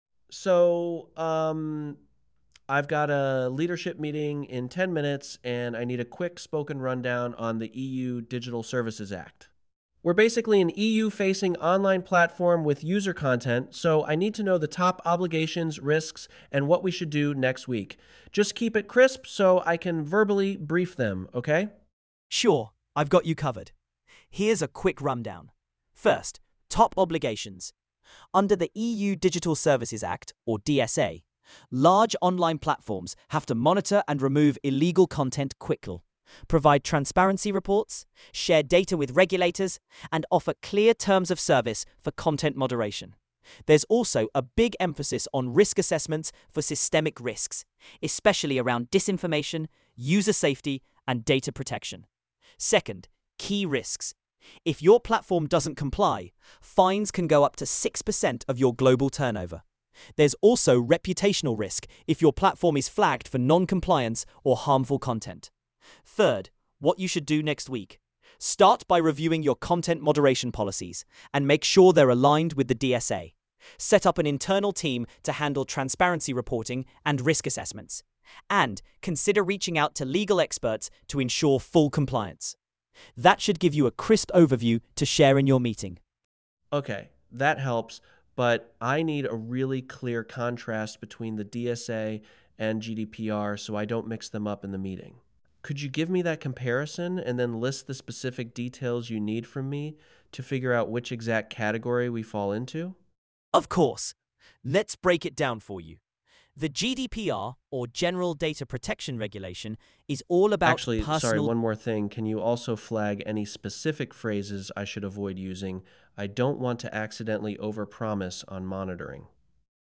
Here’s a sample audio clip from EchoChain showing an objective displacement failure in OpenAI GPT-realtime-2025-08-28. The conversation first establishes baseline context, then introduces an interruption, and we check whether the model stays aligned with the original goal after the interruption.